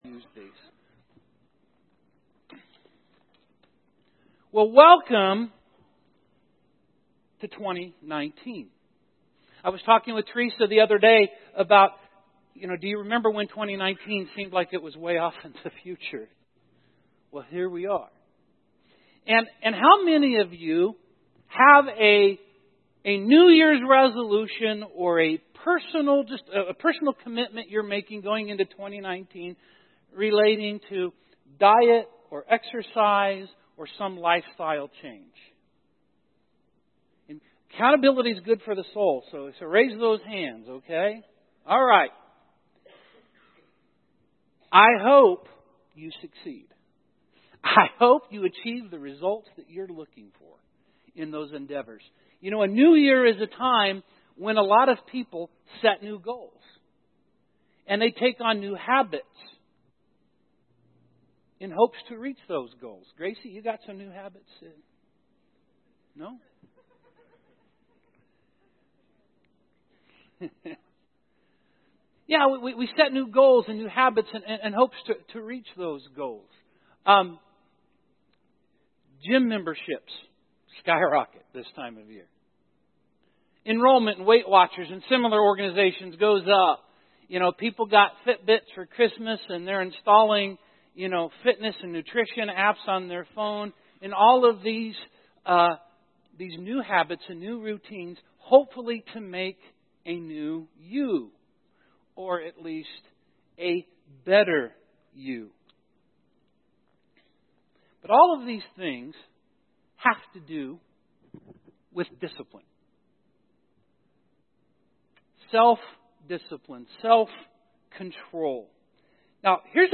Spiritual Growth Walking with God Audio Sermon Save Audio Save PDF We are starting a new series called Walking with God.